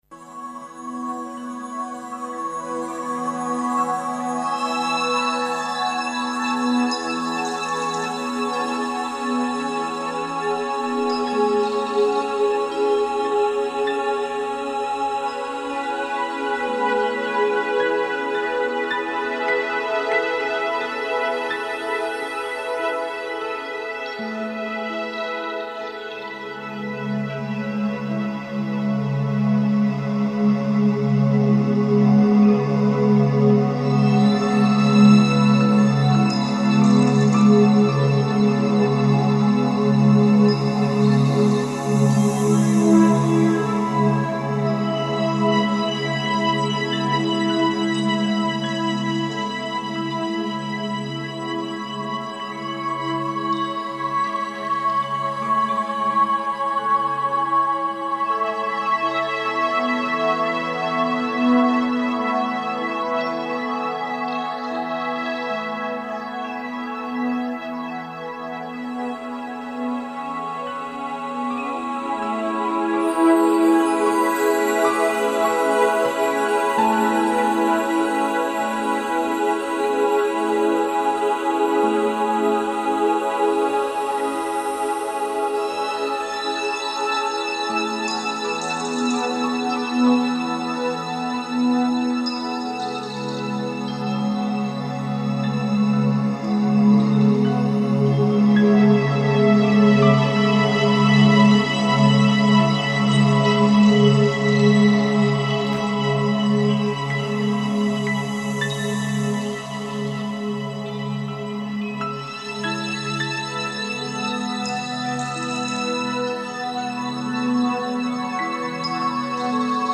Genre Música para El Alma